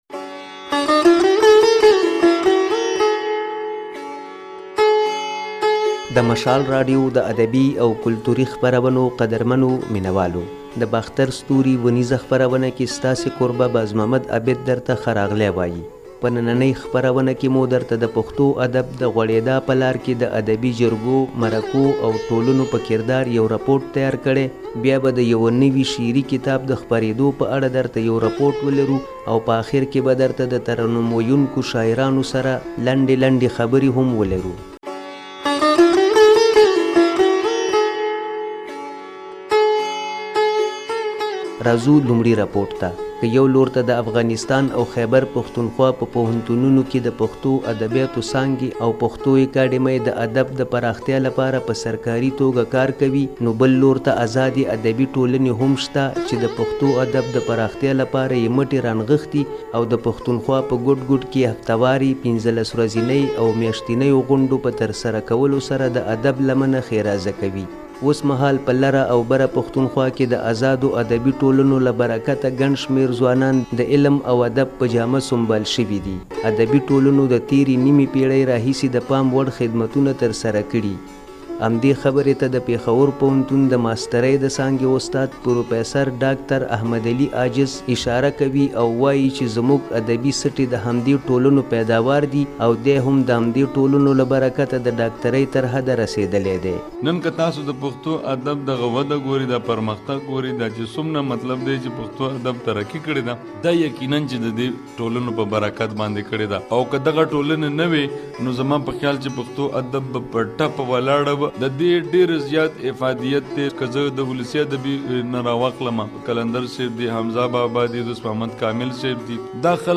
باختر ستوري کې په ترنم مشاعره لرو